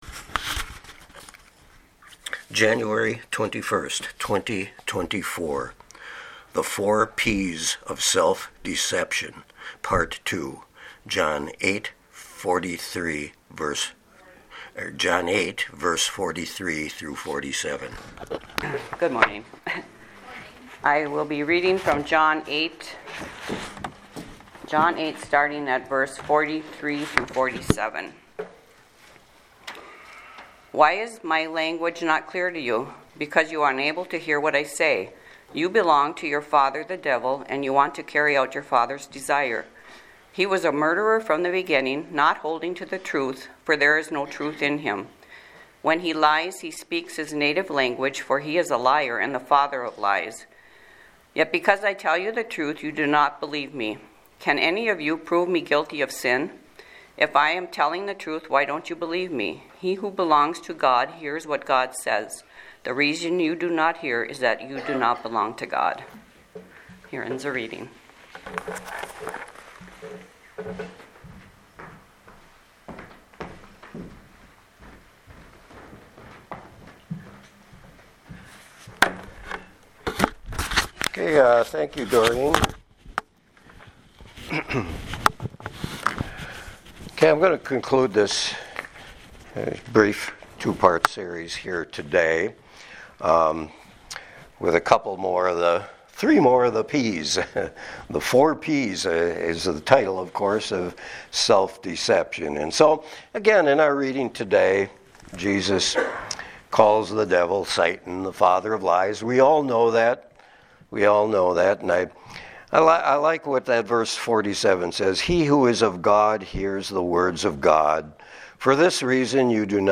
Sermons | Sand Lake Chapel
Guest Speaker